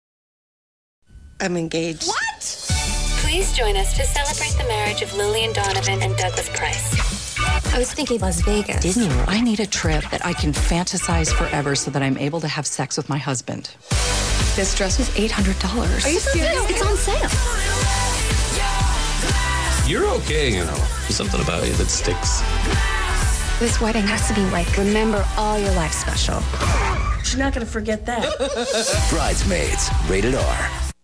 Rino Romano Brides Maids TV Spots